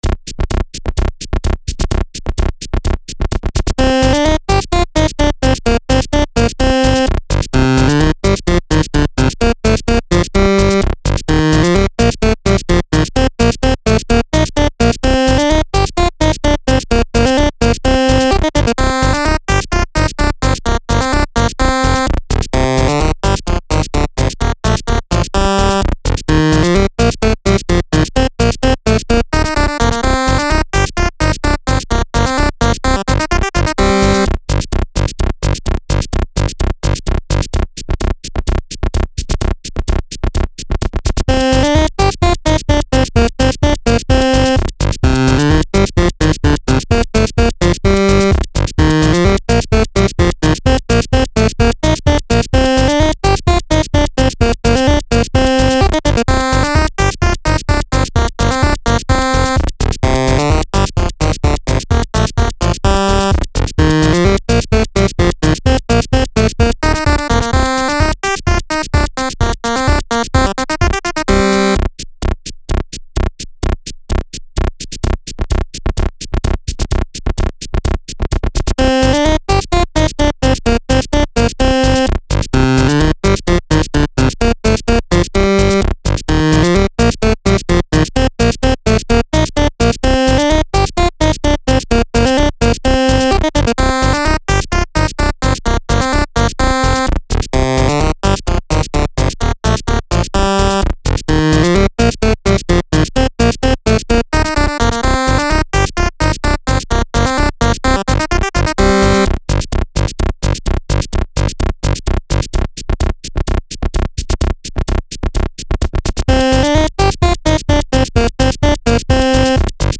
A funny little 8-bit track with 80 bars at 128 bpm.